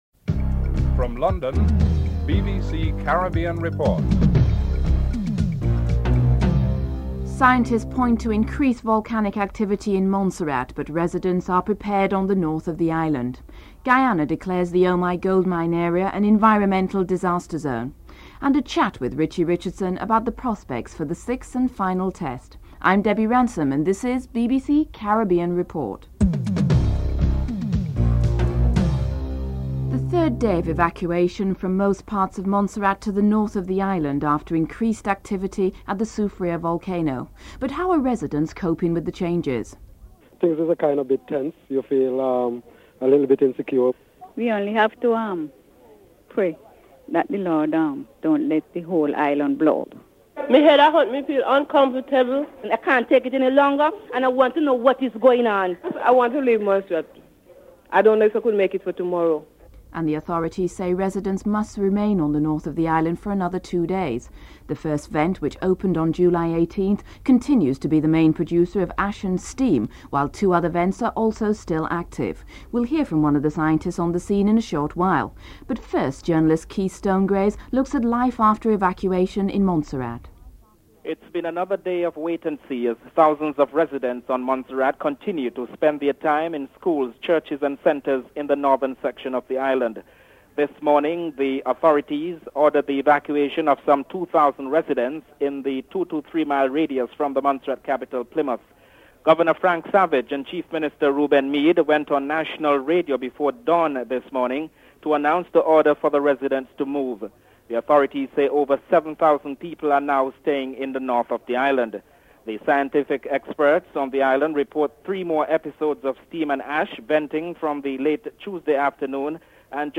6. Recap of top stories (14:41-15:05)